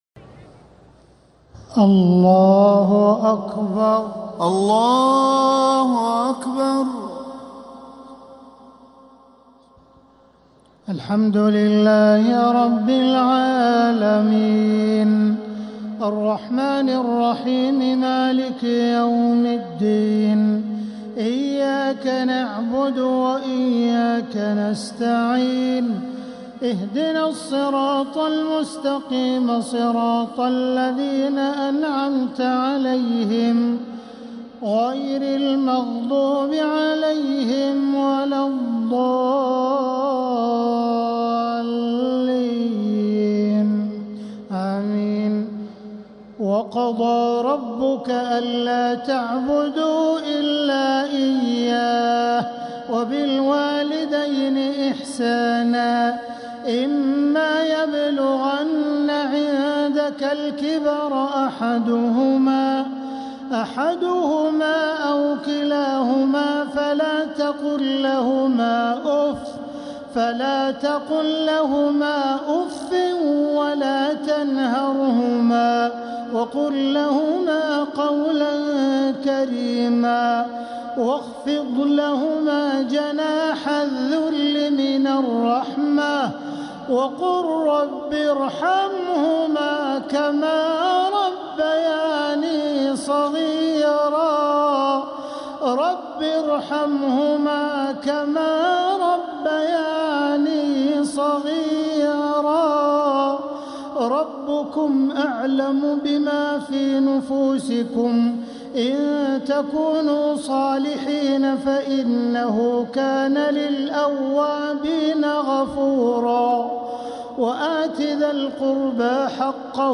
تراويح ليلة 19 رمضان 1447هـ من سورة الإسراء (23-52) | taraweeh 19th niqht Ramadan1447H Surah Al-Israa > تراويح الحرم المكي عام 1447 🕋 > التراويح - تلاوات الحرمين